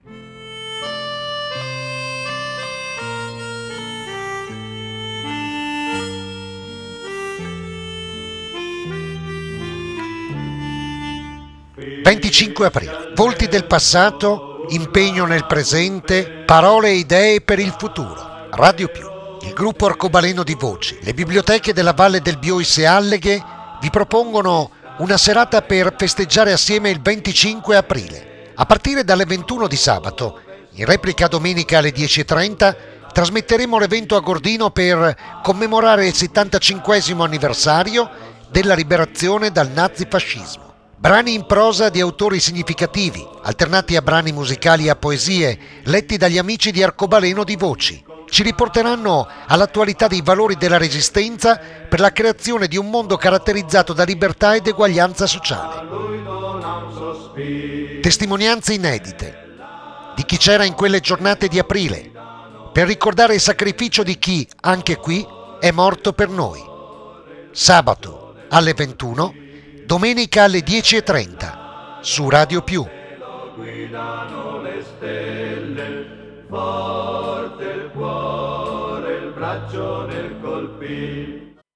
LA SIGLA